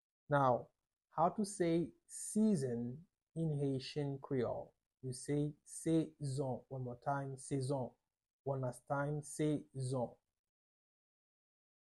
Pronunciation:
23.How-to-say-Season-in-Haitian-creole-sezon-with-pronunciation.mp3